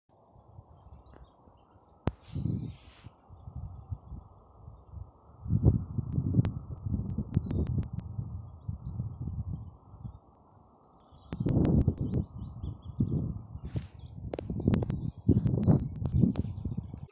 Yellowhammer, Emberiza citrinella
Ziņotāja saglabāts vietas nosaukumsSmiltenes nov. Trapenes pag
StatusSinging male in breeding season